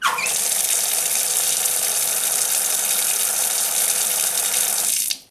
Abrir el grifo de una cocina
Sonidos: Agua
Sonidos: Hogar